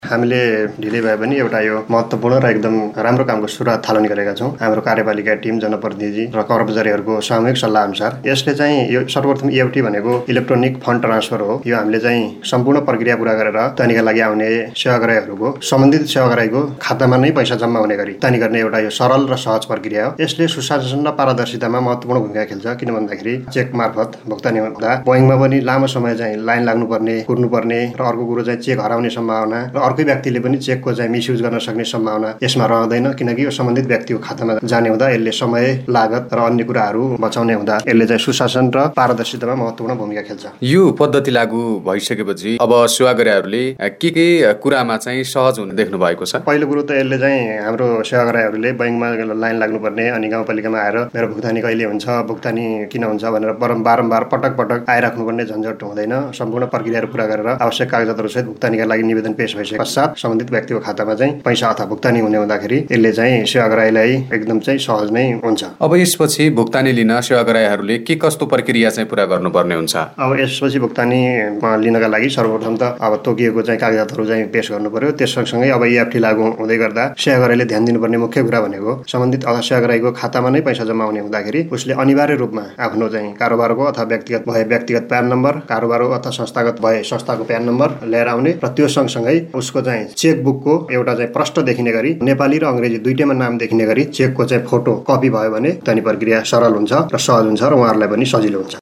कुराकानी